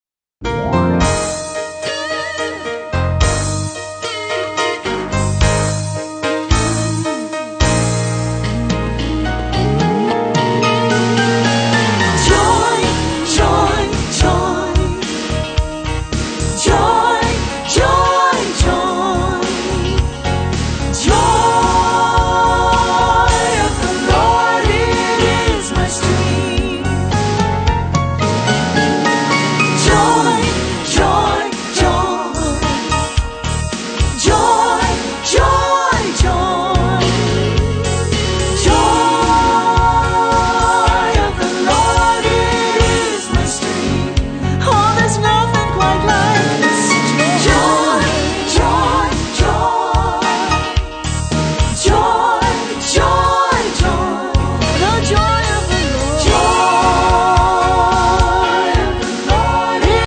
fresh, creative, inspirational, worshipful, well produced